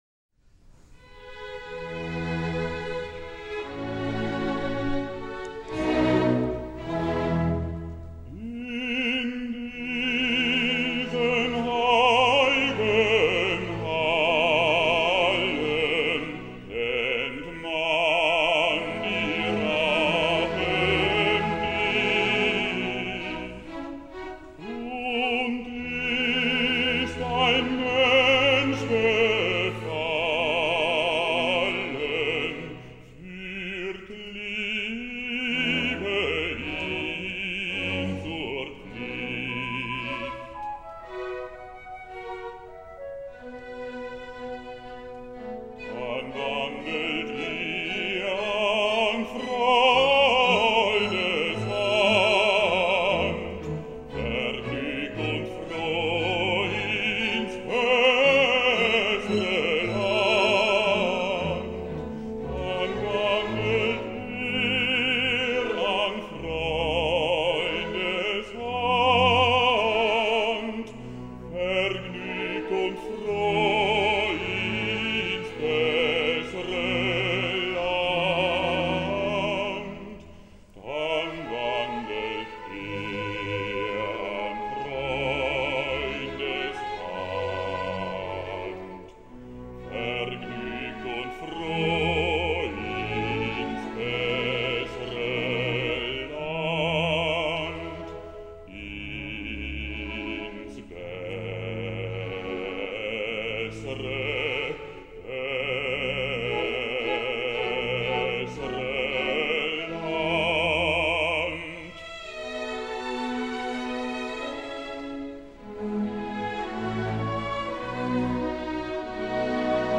Bass. W.A. Mozart: In diesen heil’gen Hallen (Sarastro’s aria, Act II).
Orchestre de la Suisse Romande. Armin Jordan, conductor.